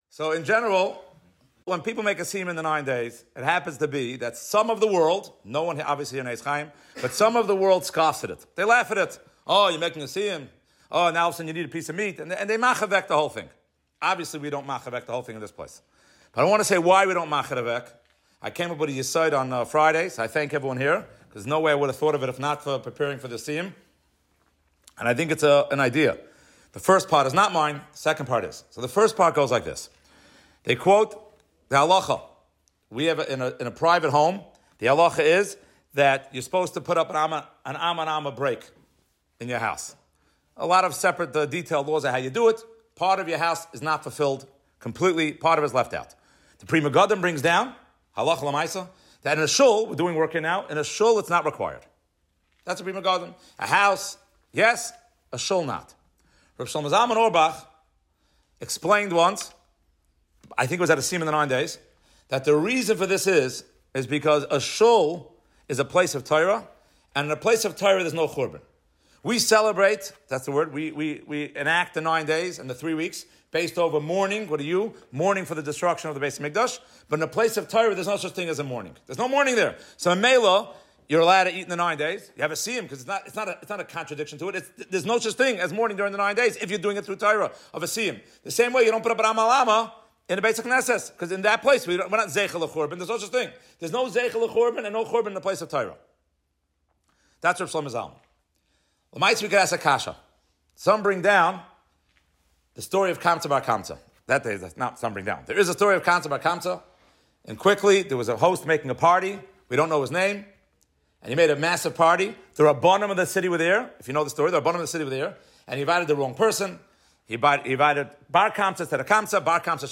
Siyum During the Nine Days at Eitz Chaim